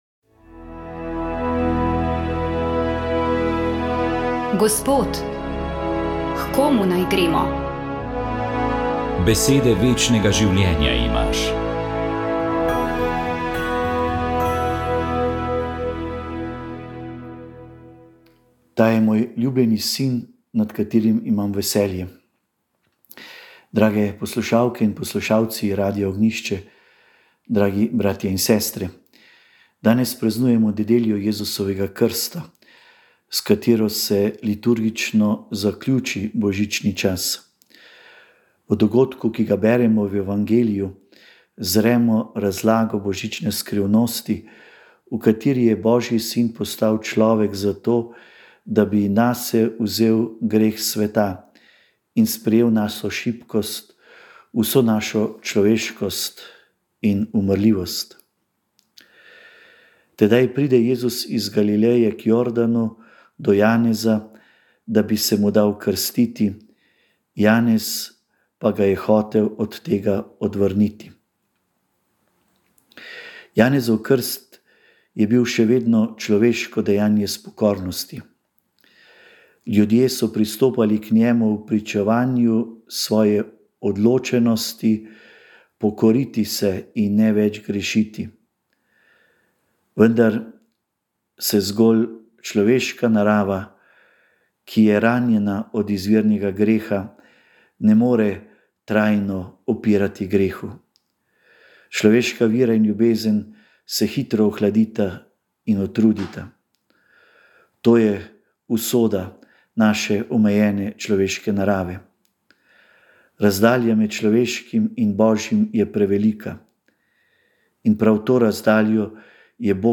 Somaševanje je vodil celjski škof msgr. Maksimiljan Matjaž.